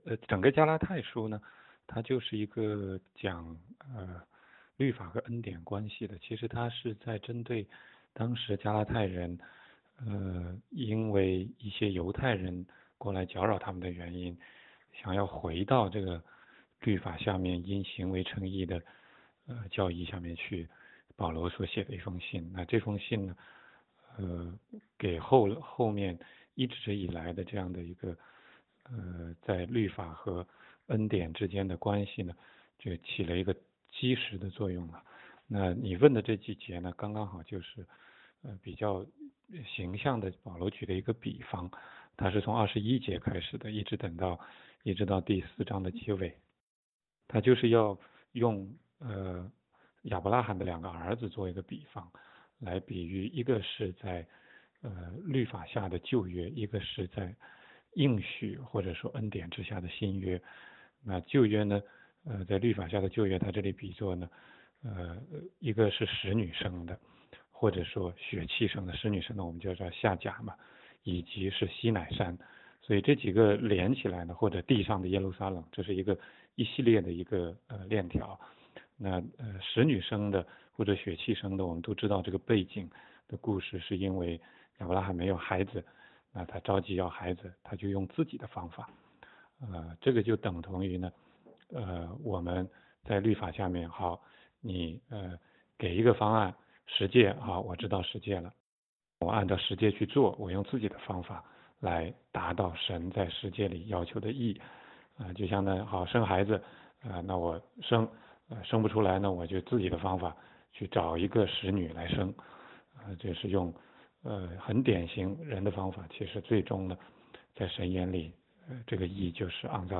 16街讲道录音 - 请分析一下加拉太书4章25-27节？
问题解答